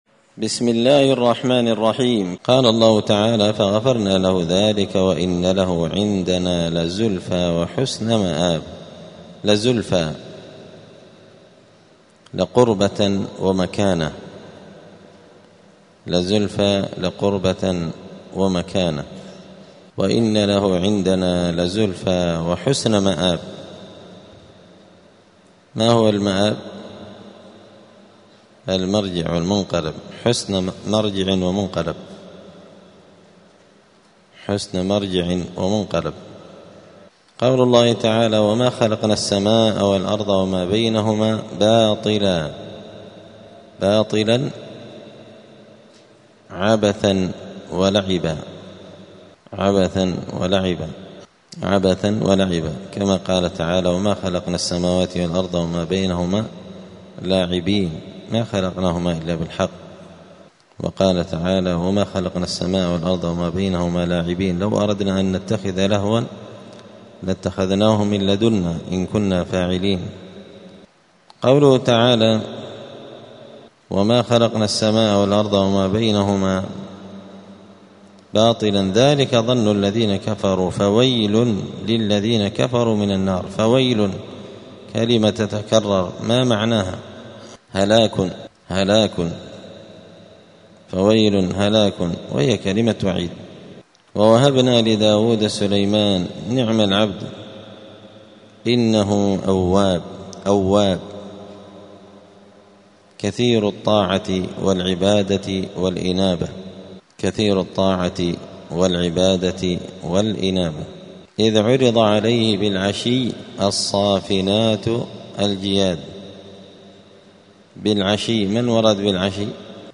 زبدة الأقوال في غريب كلام المتعال الدرس التاسع والتسعون بعد المائتين (299)